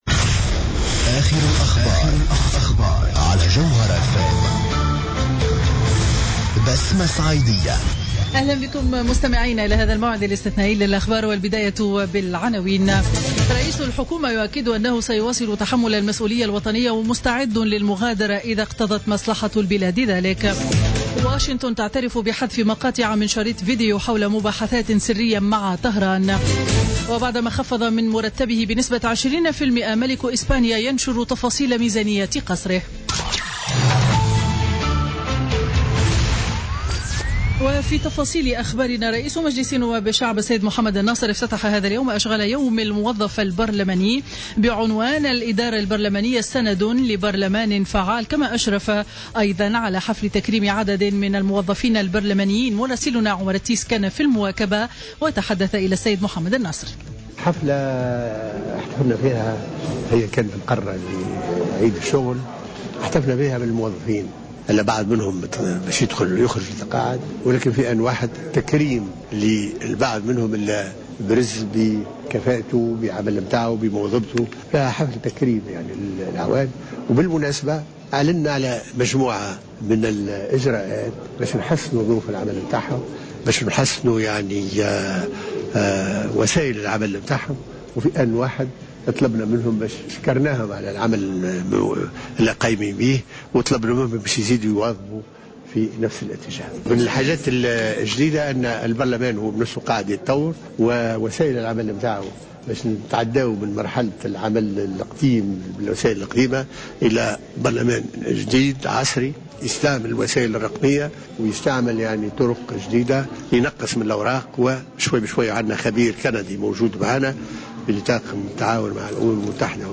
نشرة أخبار منتصف النهار ليوم الجمعة 3 جوان 2016